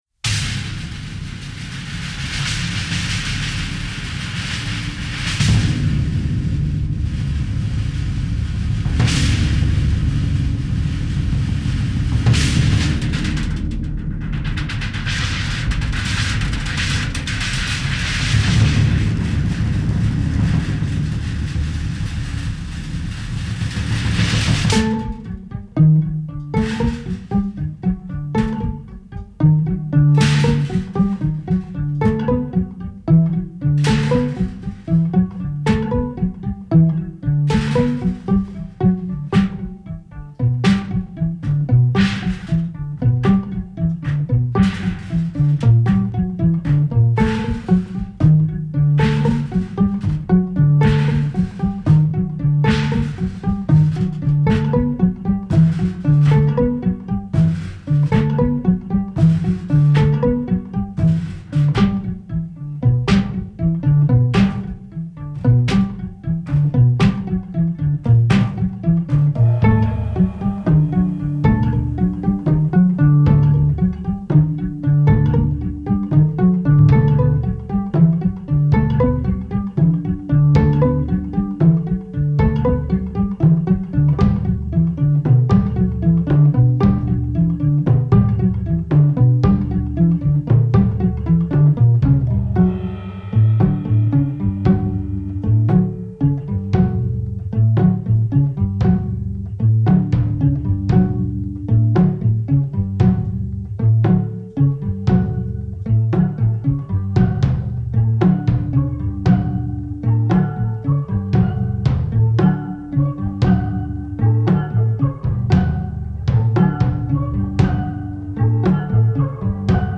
Genres: Experimental, Instrumental